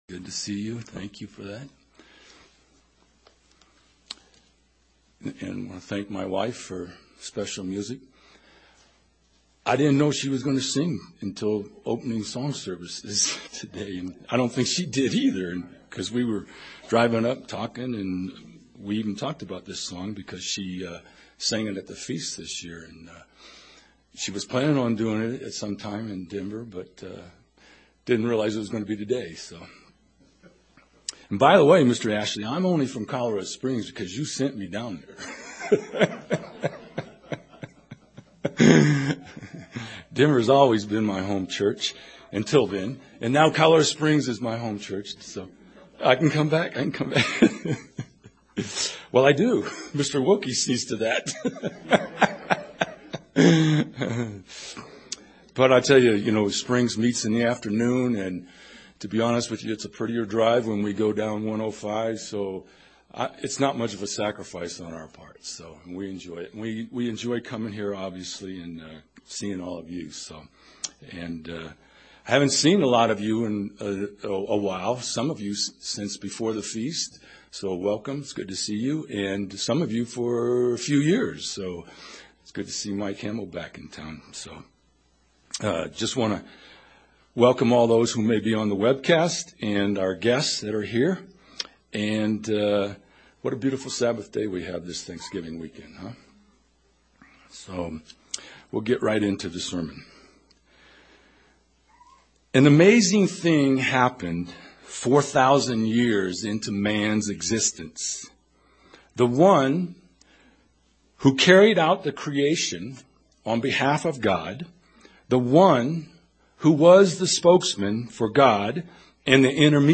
This sermon examines and unpacks Christ's answer to the Scribes and Pharisees.